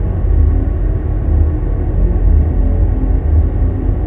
ambiances